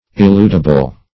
Eludible \E*lud"i*ble\, a.
eludible.mp3